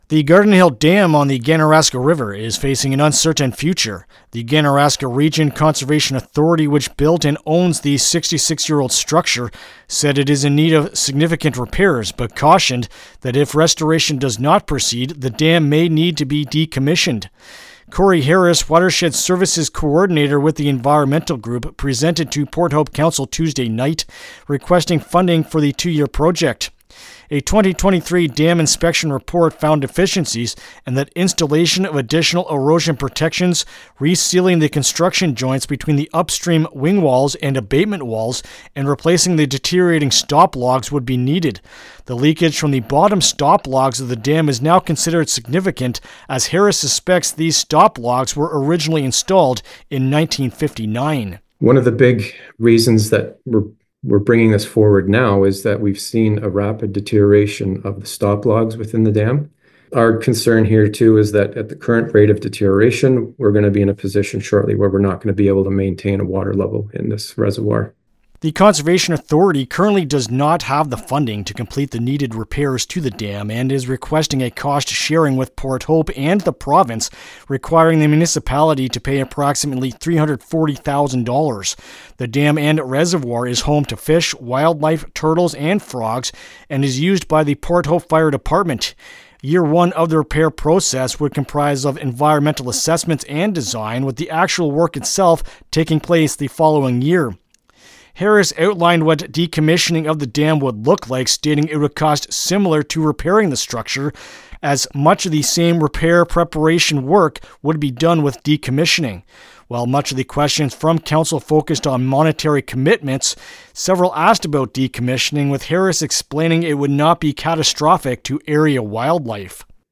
Garden-Hill-Dam-Report-LJI.mp3